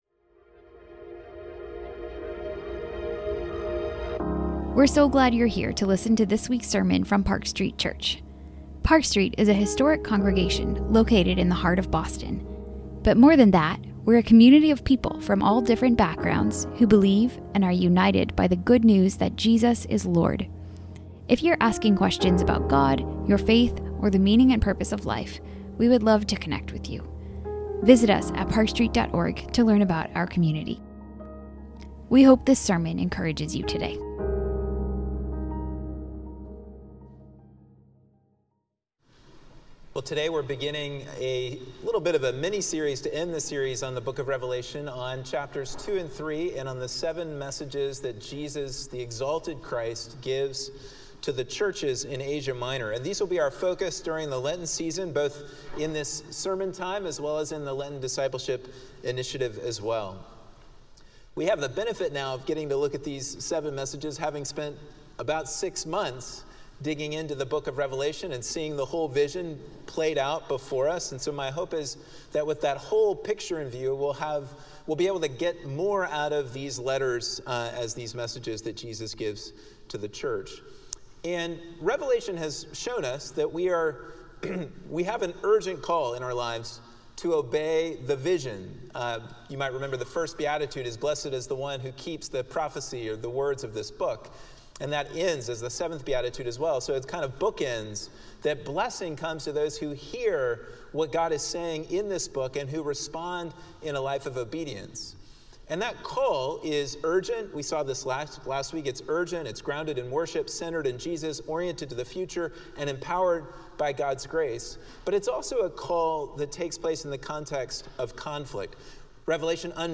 Weekly sermons from Boston's historic Park Street Church